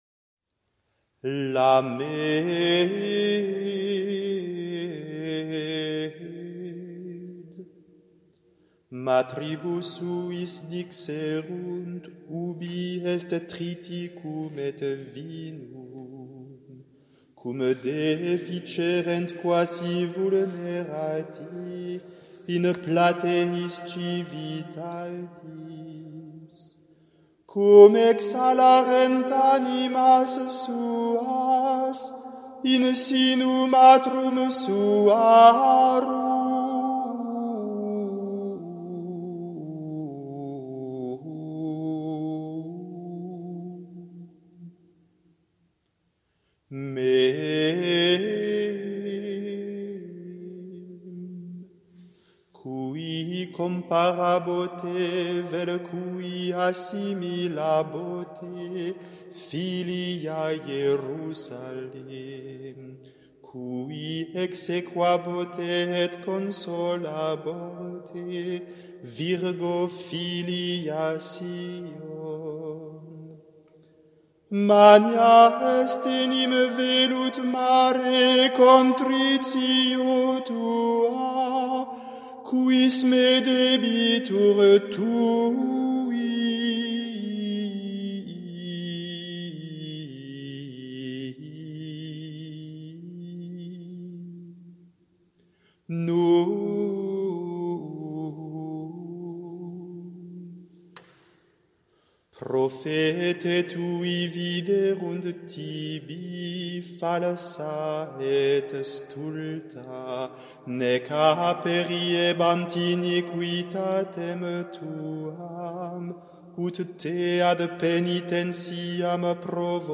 Le chant d’une lamentation (5/9)